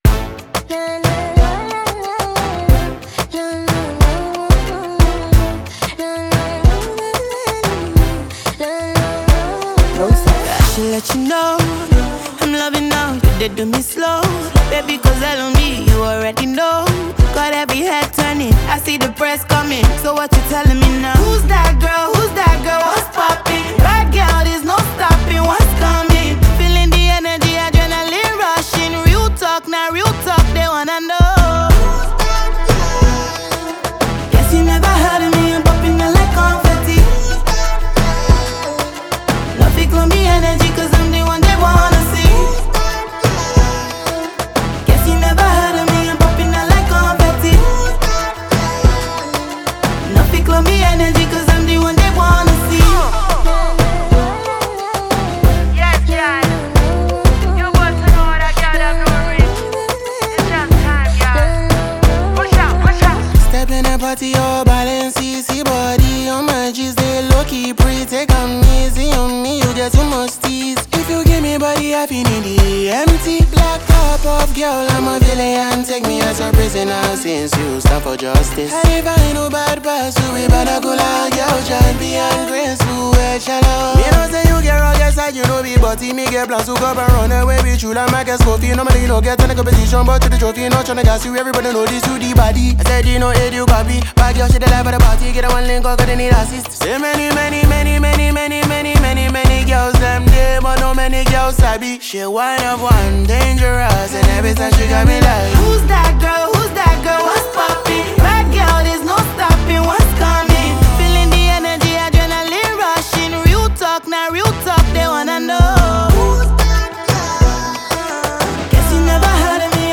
Genre: Afro-Beats, Nigerian Songs